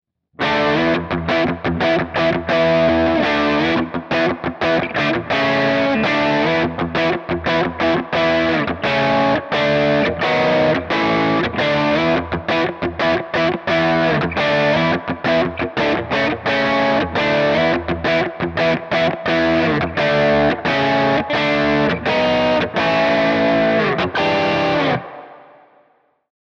In der Mittelposition liefern die beiden Tonabnehmer eine gute Grundlage für Crunch-Sounds.
Crunch, Mittelposition